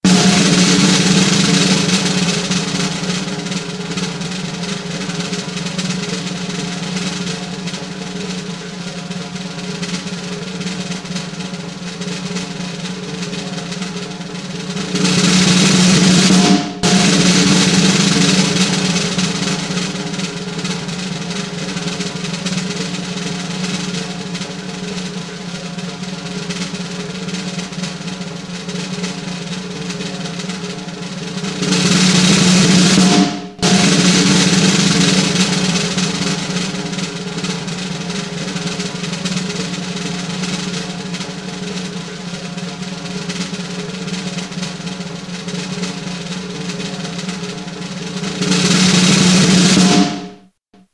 Долгий звук барабанной дроби